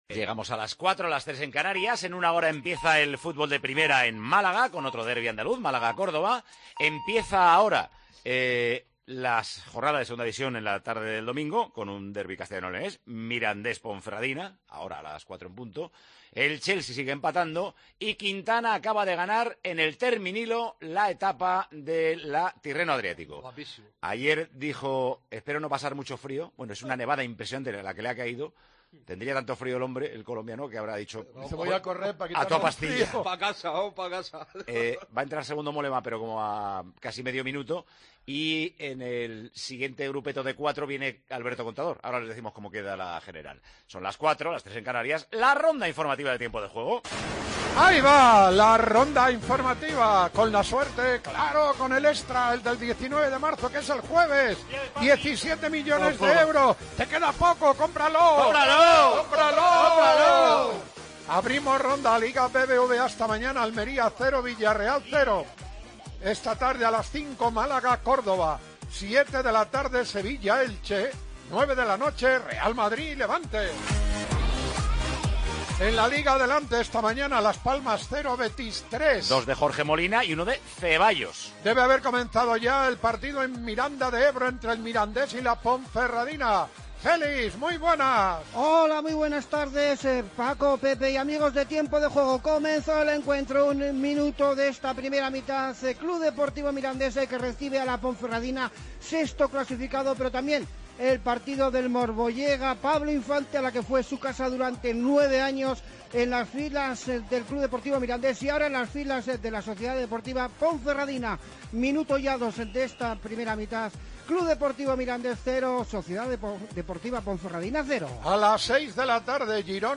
Hora, publicitat, "Ronda informativa" amb informació dels partits de futbol masculí de primera i segona divisió, bàsquet, futbol internacional, segona divisió B de futbol, Fórmula 1, tennis, hoquei herba, etc.
Gènere radiofònic Esportiu